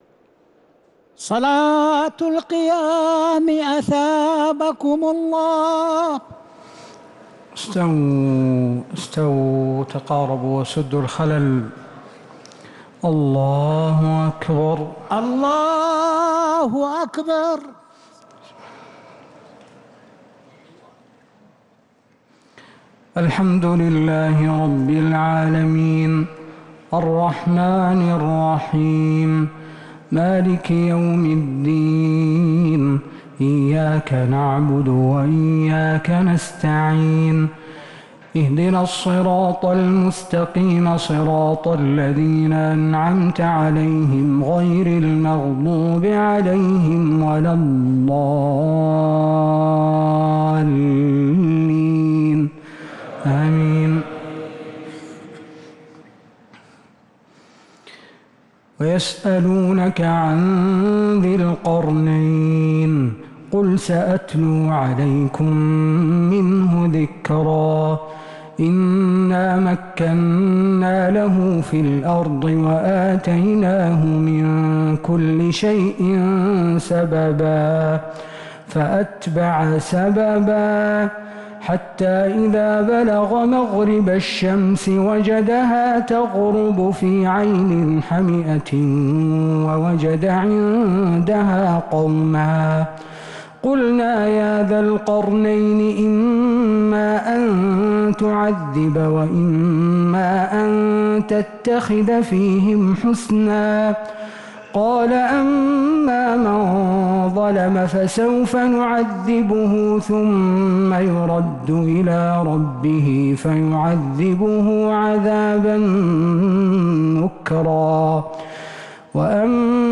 تراويح ليلة 21 رمضان 1447هـ من سورتي الكهف (83-110) و مريم (1-76) | Taraweeh 21st night Ramadan 1447H Surah Al-Kahf and Maryam > تراويح الحرم النبوي عام 1447 🕌 > التراويح - تلاوات الحرمين